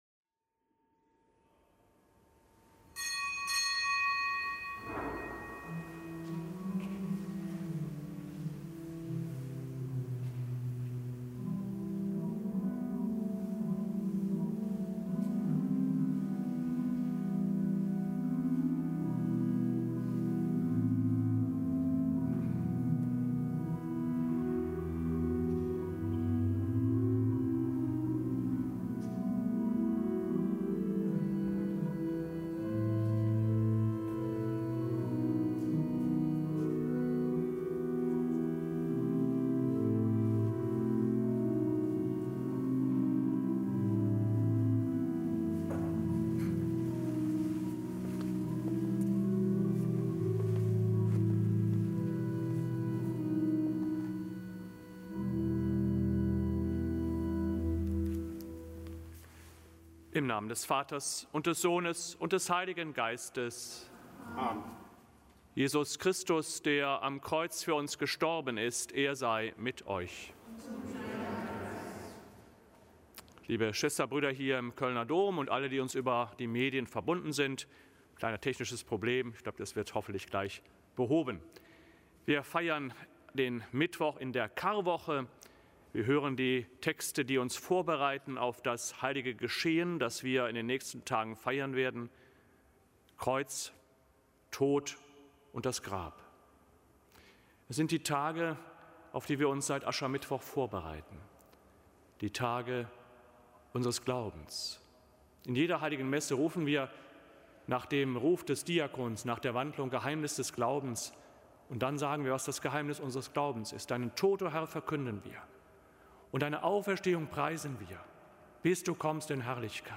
Kapitelsmesse aus dem Kölner Dom am Mittwoch der Karwoche. Zelebrant: Dompropst Guido Assmann.